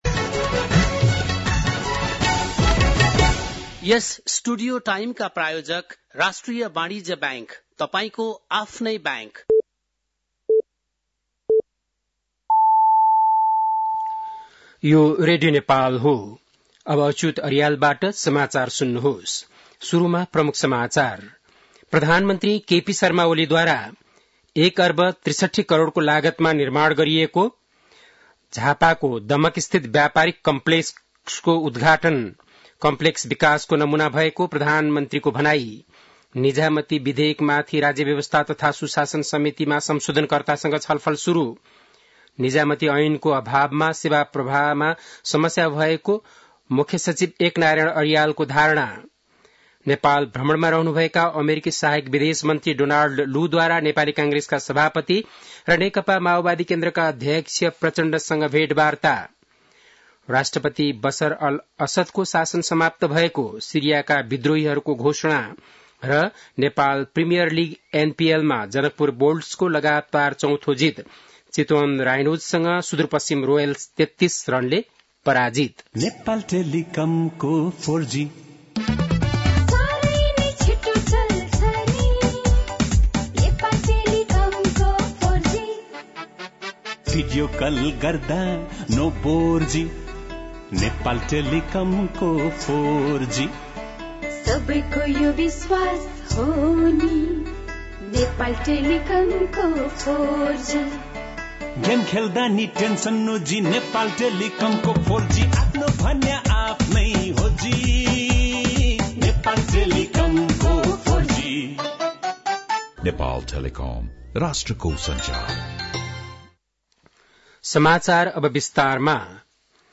बेलुकी ७ बजेको नेपाली समाचार : २४ मंसिर , २०८१
7-PM-Nepali-News-8-23.mp3